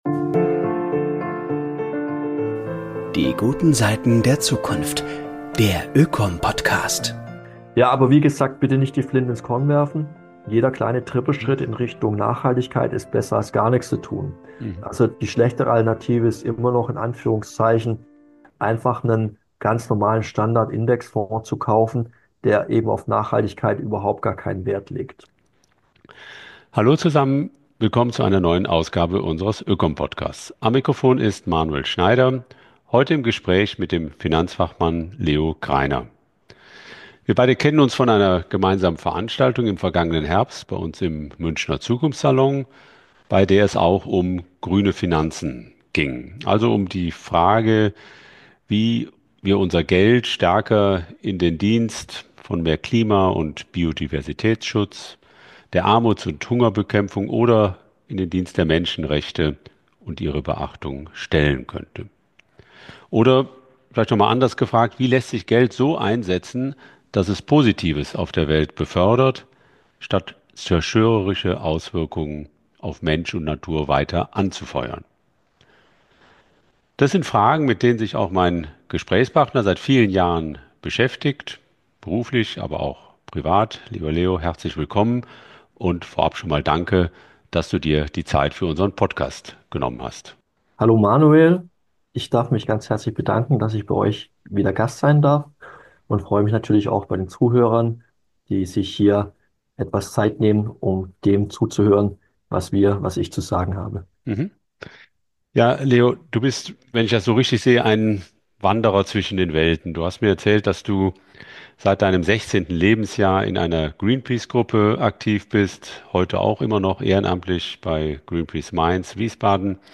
1 Grüne Finanzen. Warum es gar nicht so leicht (aber sinnvoll) ist, sein Geld nachhaltig anzulegen 59:25 Play Pause 6h ago 59:25 Play Pause Später Spielen Später Spielen Listen Gefällt mir Geliked 59:25 [Gespräch